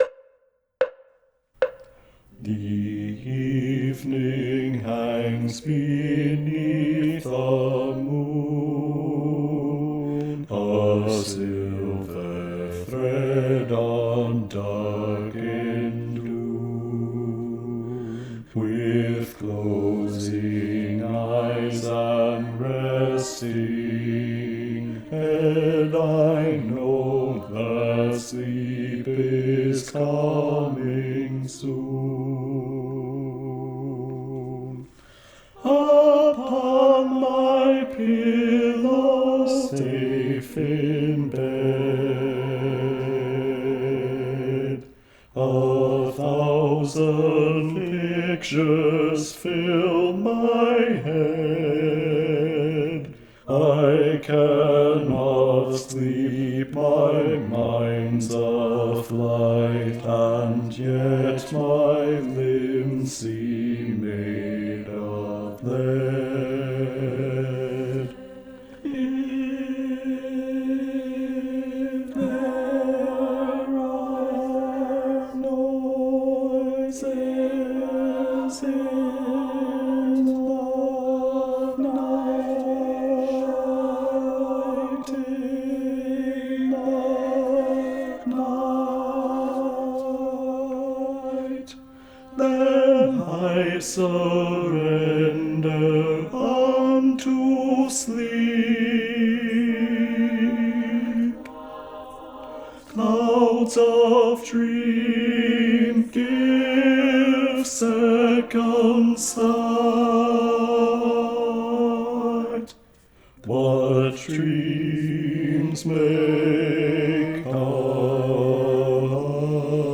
- Œuvre pour chœur à 8 voix mixtes (SSAATTBB)
Bass 1 Live Vocal Practice Track